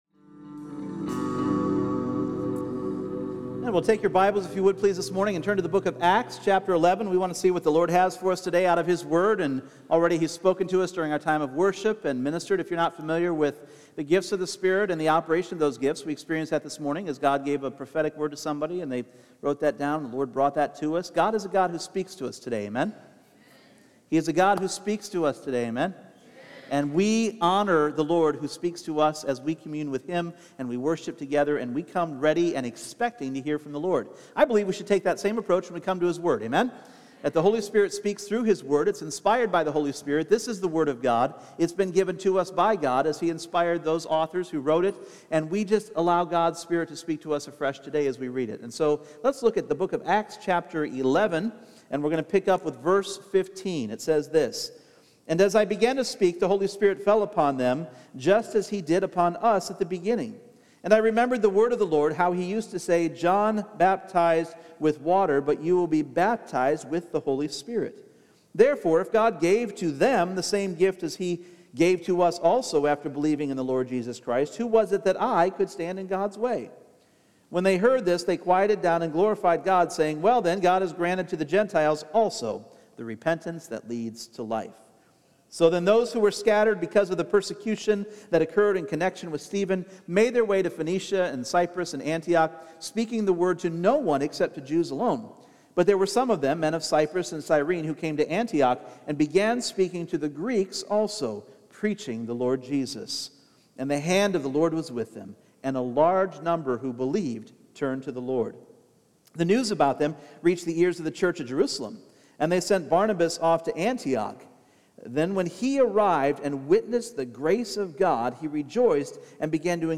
Individual Messages Service Type: Sunday Morning Grace.